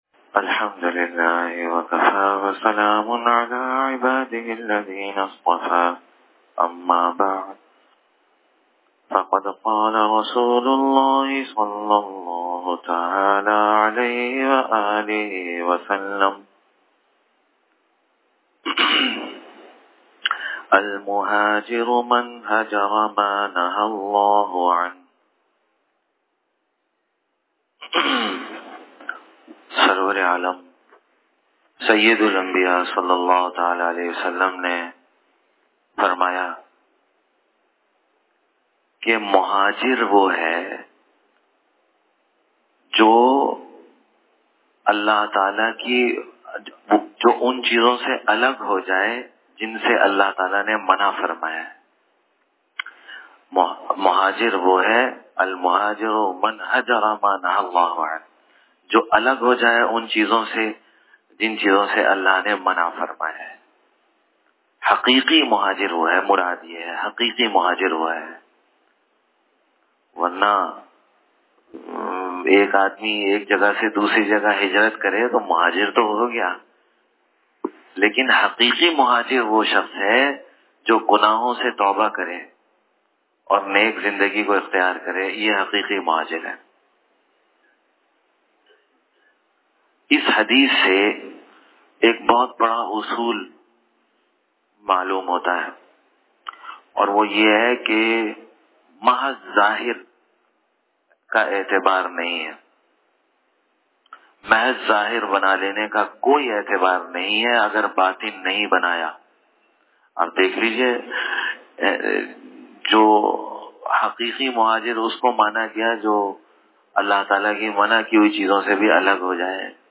Dars e Hadees 27th Ramzan 1439Hijri